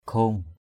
/kʱlo:ŋ/ (d.) trục chỉ = ensouple du métier à tisser.